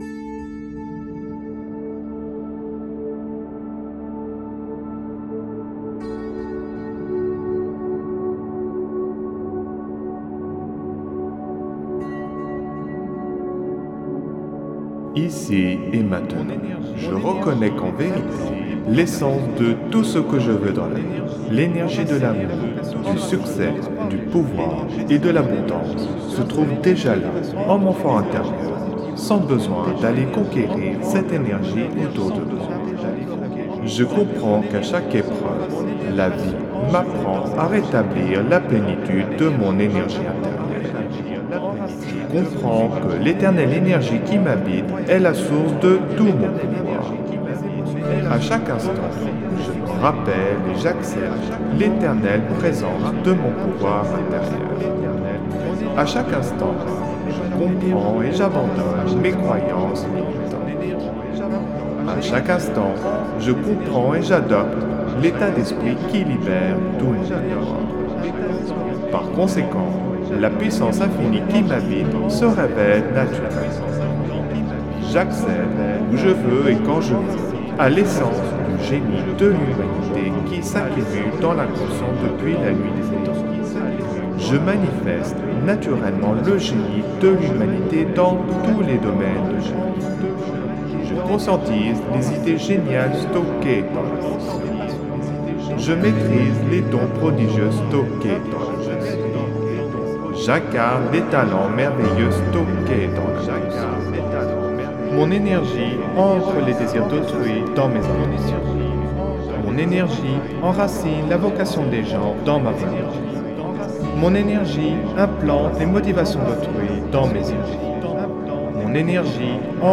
(Version ÉCHO-GUIDÉE)
Alliage ingénieux de sons et fréquences curatives, très bénéfiques pour le cerveau.
Pures ondes gamma intenses 73,66 Hz de qualité supérieure. Puissant effet 3D subliminal écho-guidé.
SAMPLE-Persuasion-3-echo.mp3